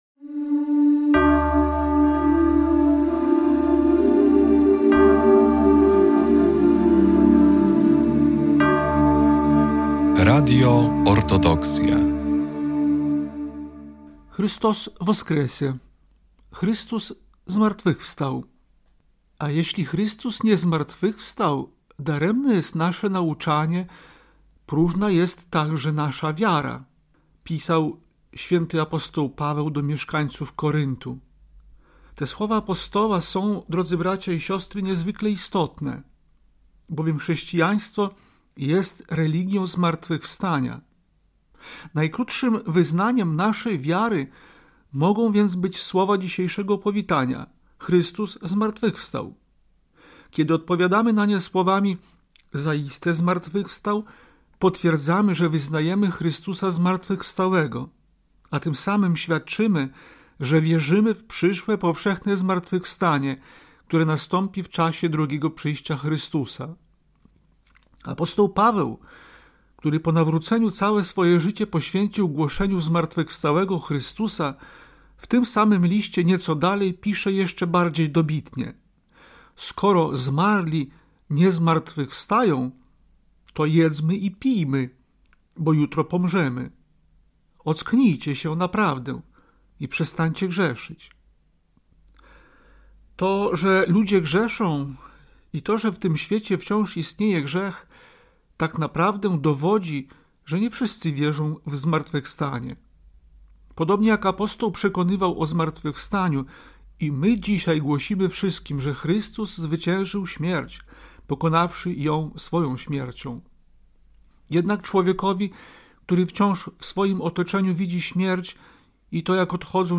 Zapraszamy do wysłuchania paschalnego orędzia Jego Ekscelencji Najprzewielebniejszego Jakuba Arcybiskupa Białostockiego i Gdańskiego.